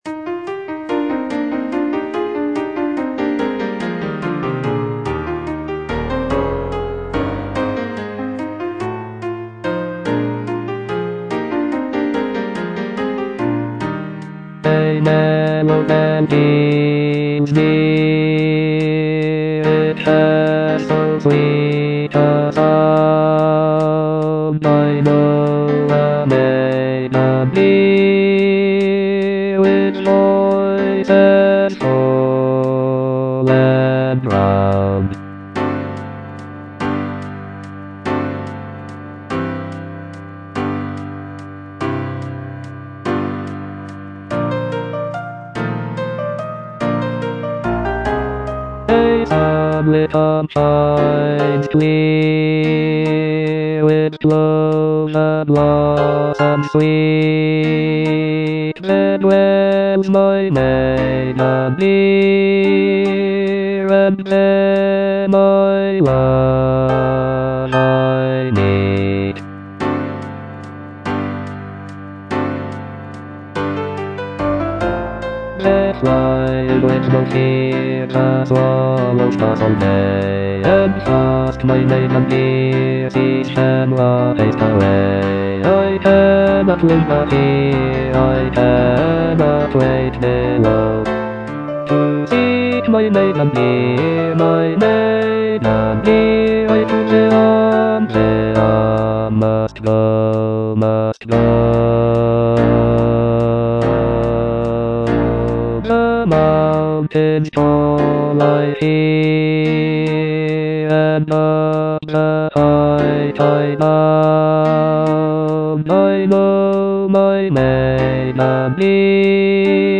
E. ELGAR - FROM THE BAVARIAN HIGHLANDS On the alm (bass II) (Voice with metronome) Ads stop: auto-stop